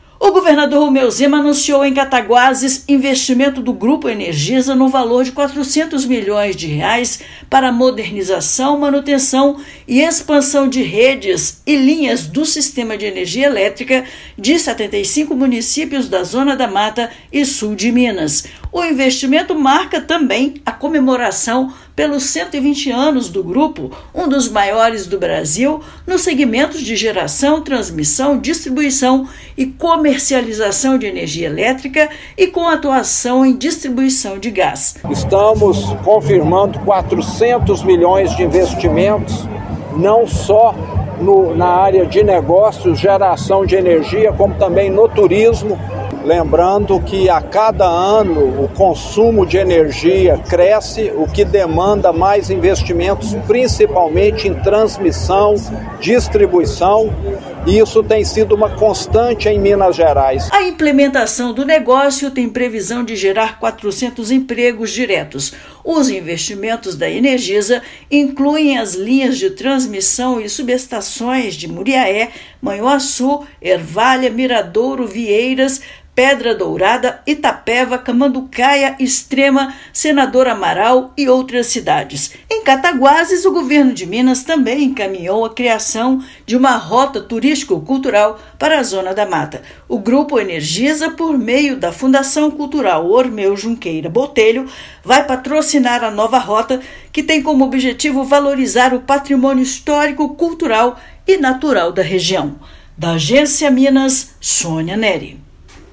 Evento de comemoração pelos 120 anos da empresa marca anúncio de modernização e manutenção de redes e linhas de 75 municípios atendidos na Zona da Mata e no Sul de Minas. Ouça matéria de rádio.